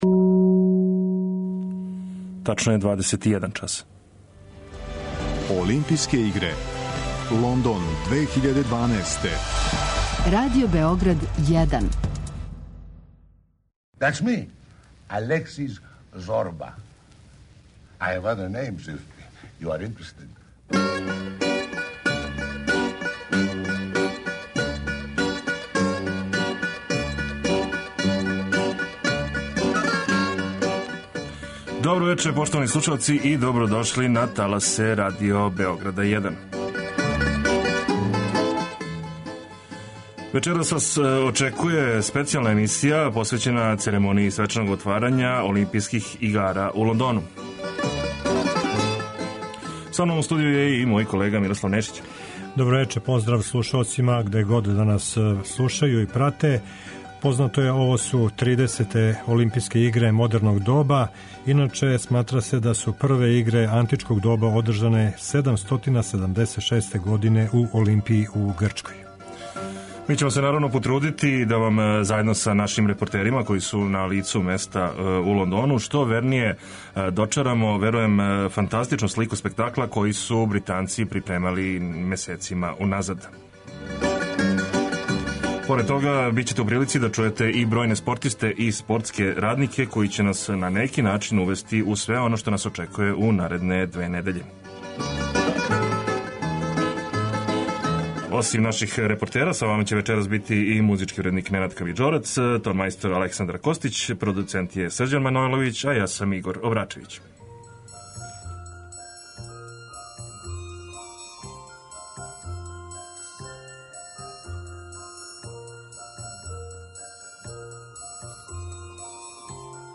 Svečanost će započeti oglašavanjem naročitih zvona koje je izlila livnica "Vajtčapel Faundri", koja je u Ginisovu knjigu rekorda ušla kao najstarija britanska firma za proizvodnju zvona iz 16. veka.